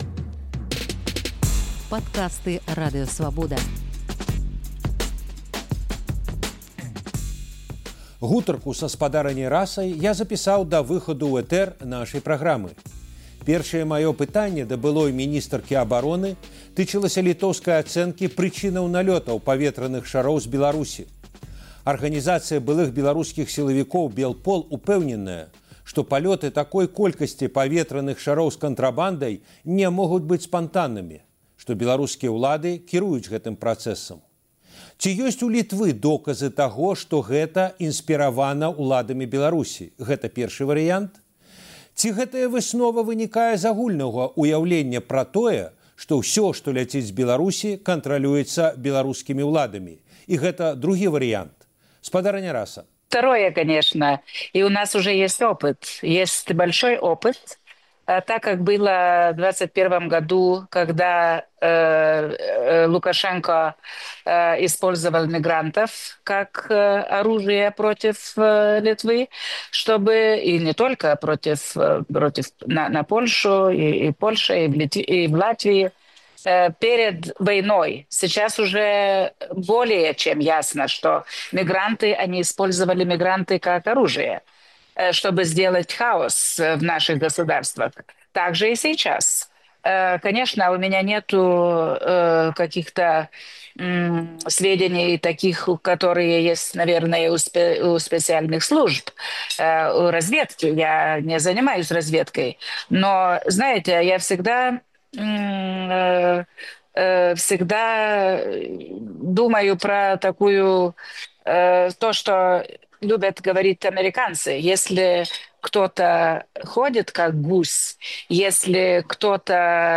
адказвае дэпутатка Эўрапарлямэнту, былая міністарка абароны Літвы Раса Юкнявічэне.